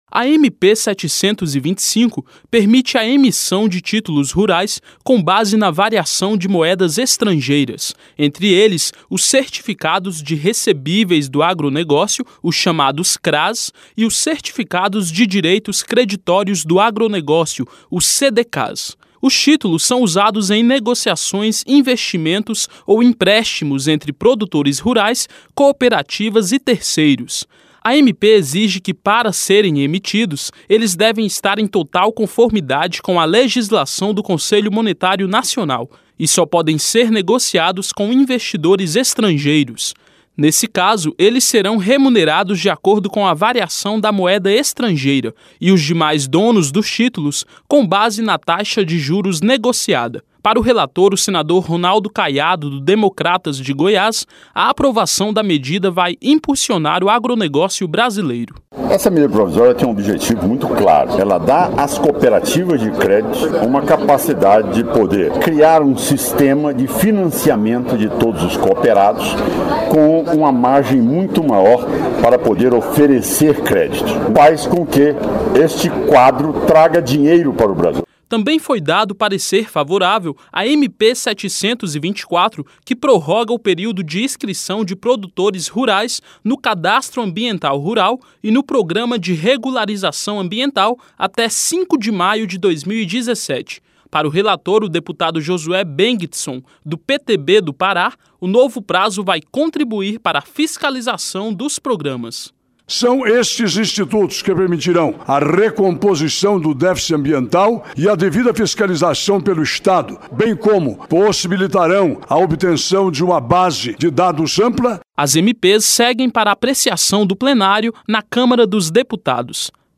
Transcrição